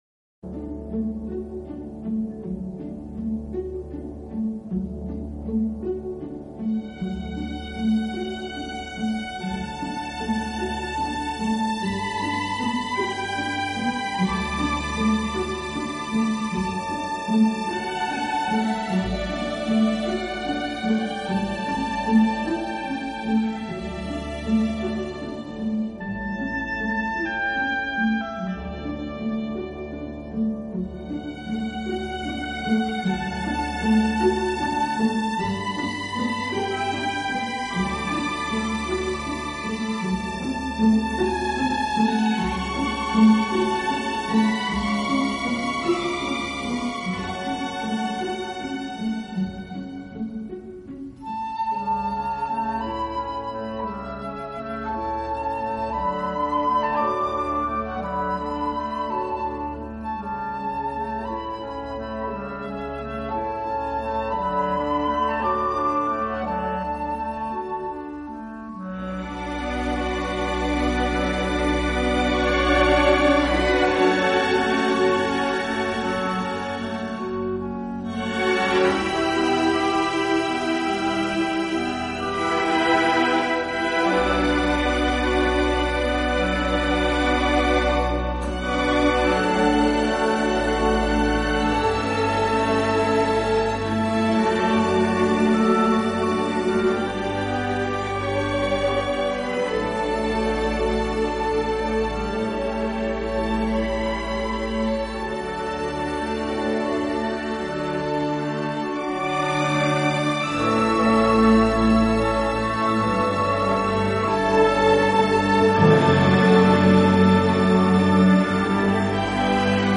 的音响，这种富有特色的弦乐演奏，使他的音乐流传世界各地。